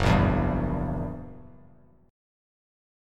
Gb7b9 Chord
Listen to Gb7b9 strummed